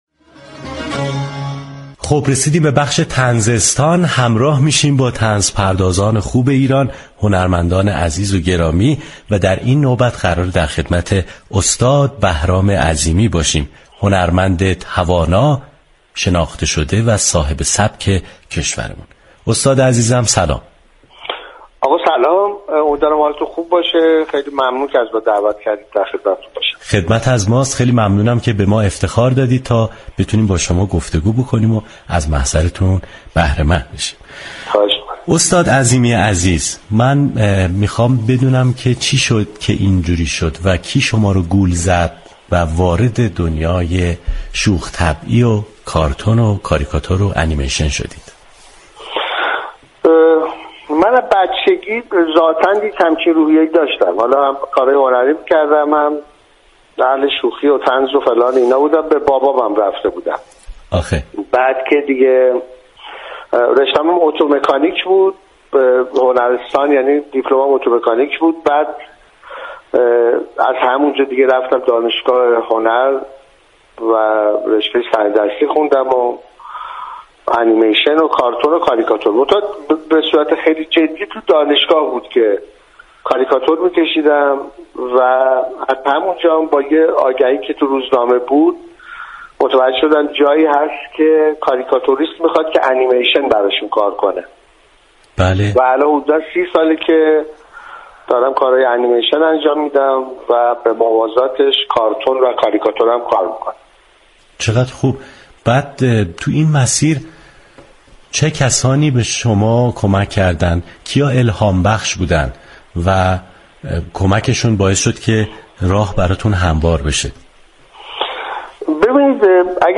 بهرام عظیمی كارگردان پویانمایی، فیلمنامه‌نویس، طراح كاراكتر و كاریكاتوریست در گفتگو با رادیو صبا درباره خلاقیتش و ورودش به حرفه هنر توضیح داد.